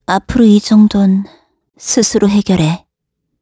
Gremory-Vox_Victory_kr.wav